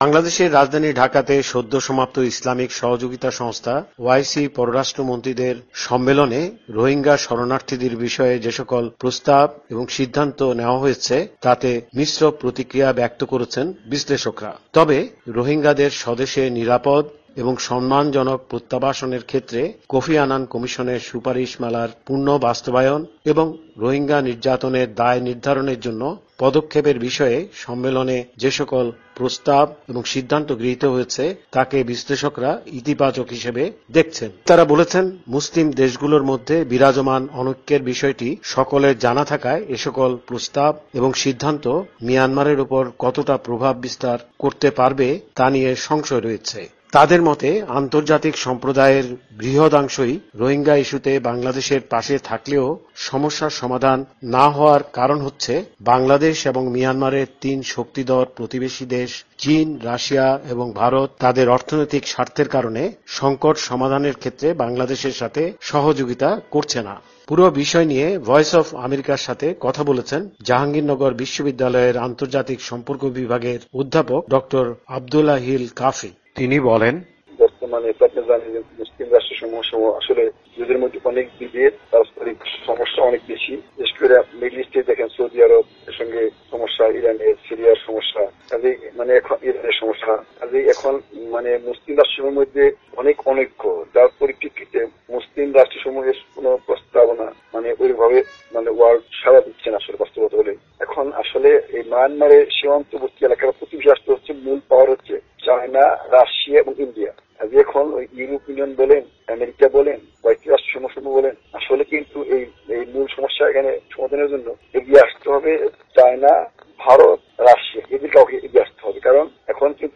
(Actuality)।